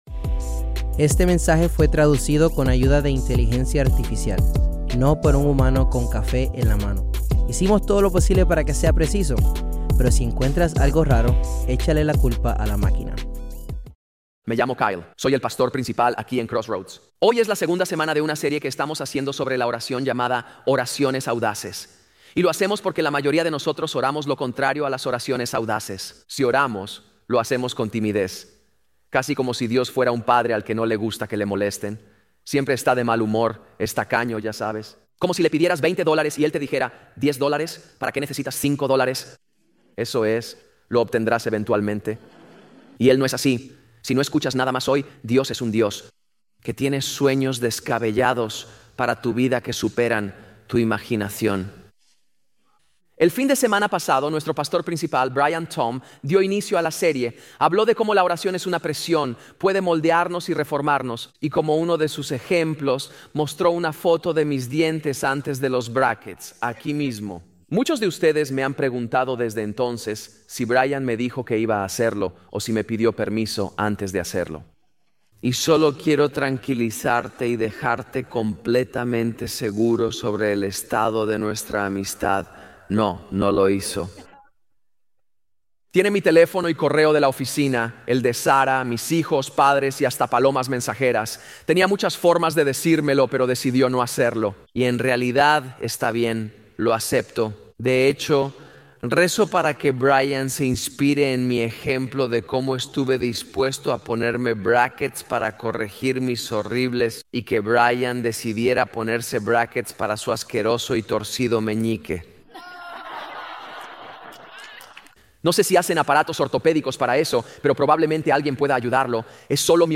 Grabado en vivo en Crossroads, en Cincinnati, Ohio.